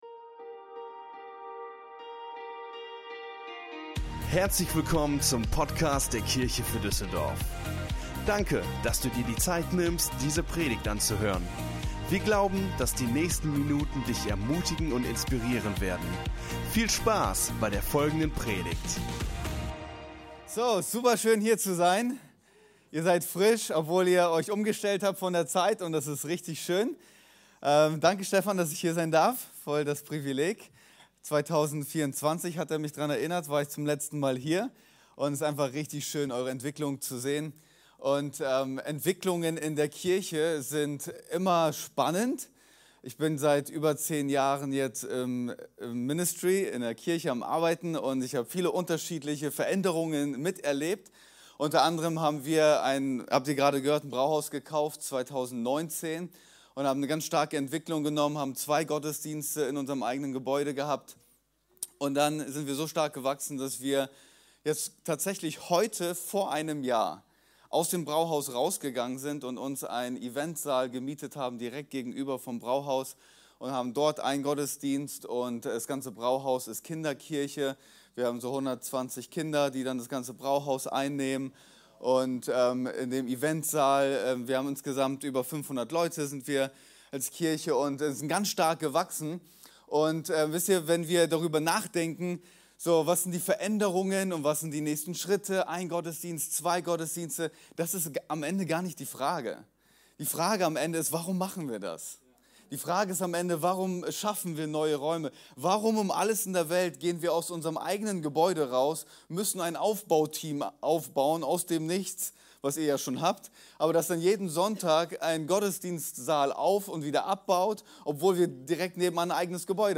In dieser Predigt geht es darum, wie ein klares Warum unser Leben verändert.